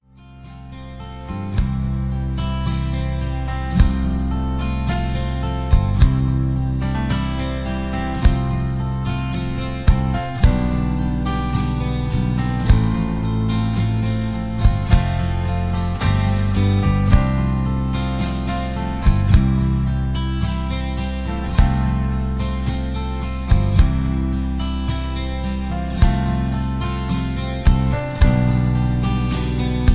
backing tracks , karaoke